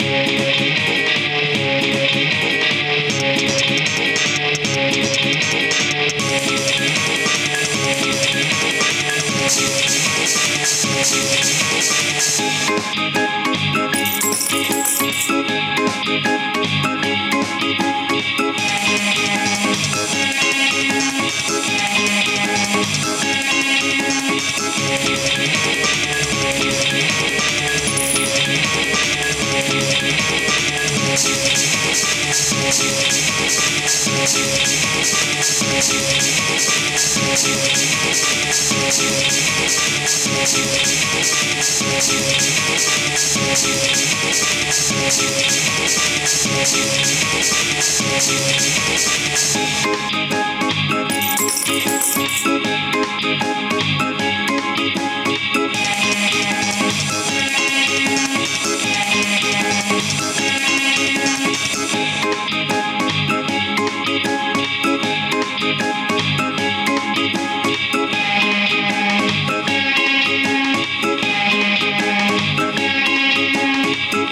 少し切なめのバトル系ギター曲です！原曲よりもさらに疾走感をイメージしてアレンジしました！
ループ：◎
BPM：155
キー：C#m
ジャンル：あかるい、みらい
楽器：ギター、ピアノ、シンセサイザー